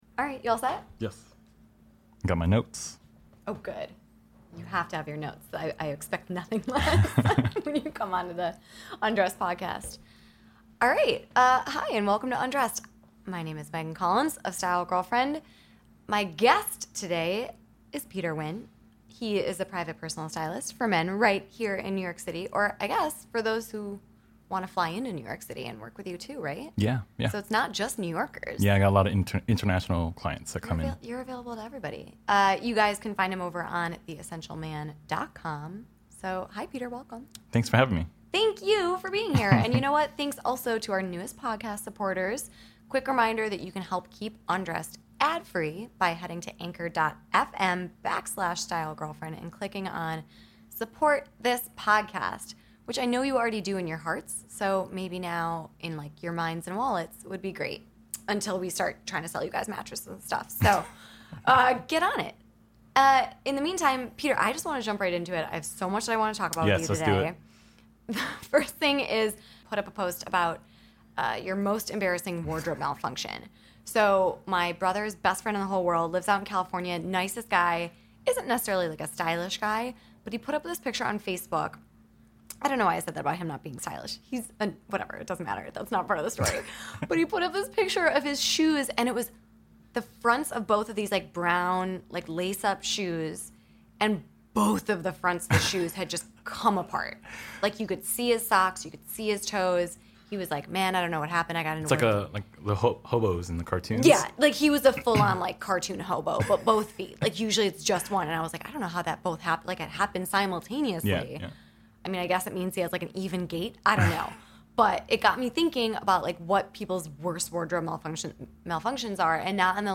in the studio to talk about their most embarrassing wardrobe malfunctions, the year's best magazine covers, and the most expensive grooming product they each own.